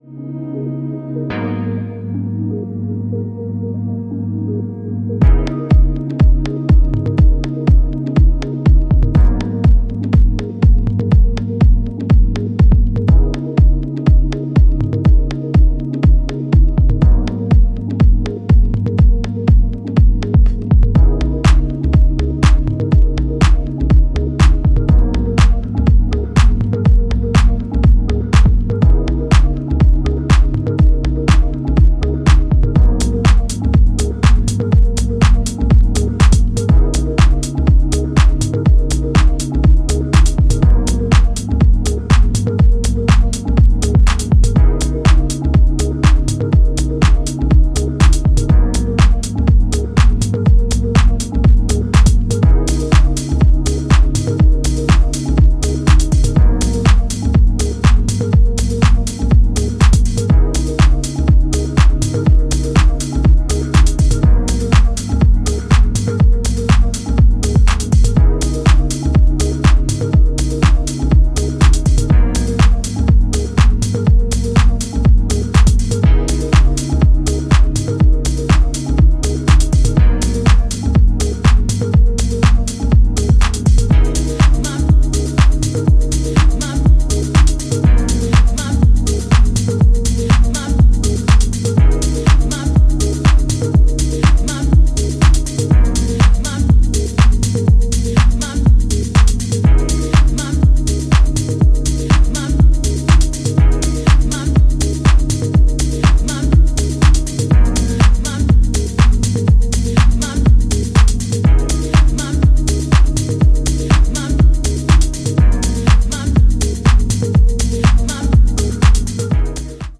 ラフな質感とメランコリーがフロアに浸透していく、非常にエッセンシャルな内容です！